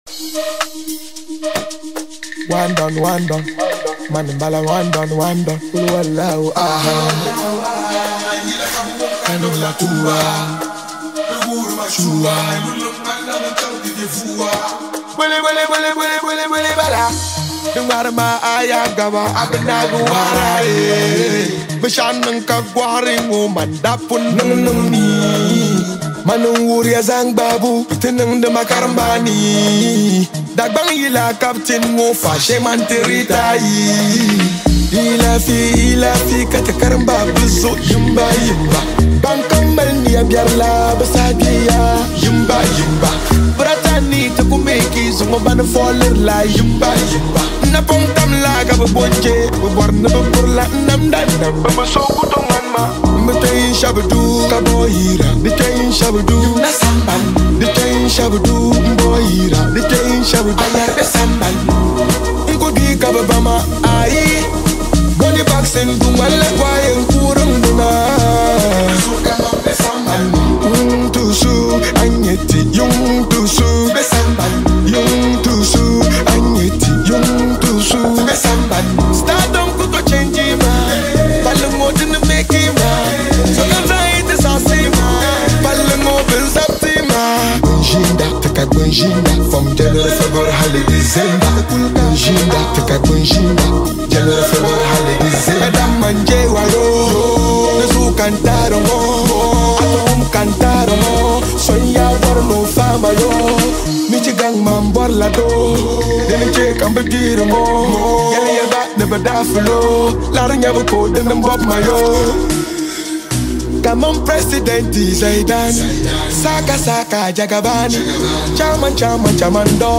full of energy, vibes, and authenticity